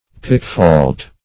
Pick-fault \Pick"-fault`\, n. One who seeks out faults.